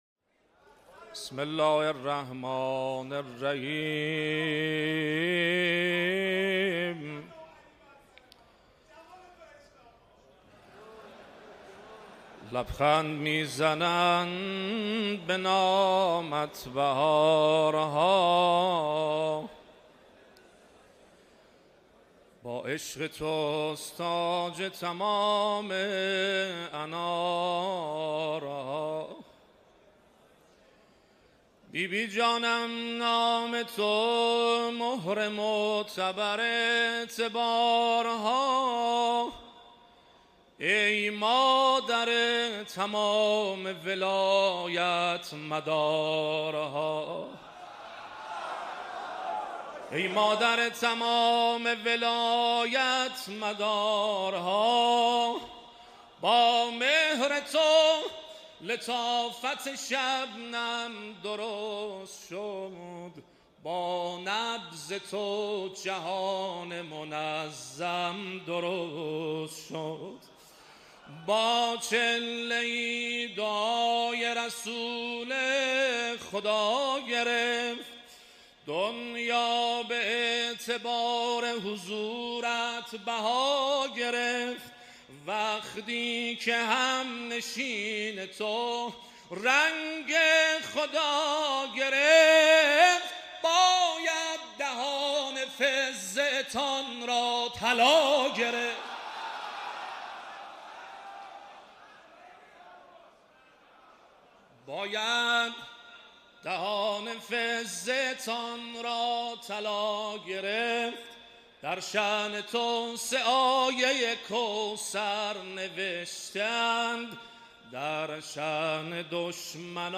مولودی آذری مولودی ترکی
در محضر رهبر معظم انقلاب